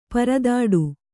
♪ paradāḍu